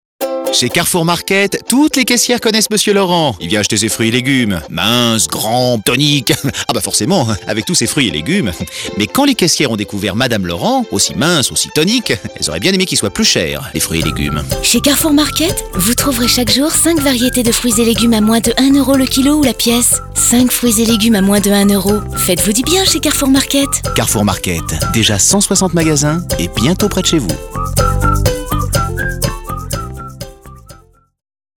Défi relevé avec cette campagne radio écrite pour Carrefour Market, avec la voix d’Emmanuel Curtil (la voix française de Jim Carrey) et des portraits de clients dans lesquels on sent toute la bienveillance de l’enseigne et son engagement à leur rendre service.